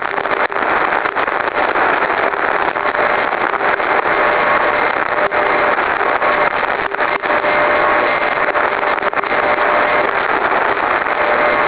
In the brief wave file below, the CW signal has been attenuated by 18 dB prior to mixing with the noise.
It consists of a number of carriers of different intensity, superimposed on the usual LF background of static crashes. An ear that is "tuned" to 400 Hz CW will be able to pick out the faint CW signal, especially if the recording is played back in continuous loop mode.
CW at -18 dB, 2 kHz noise bandwidth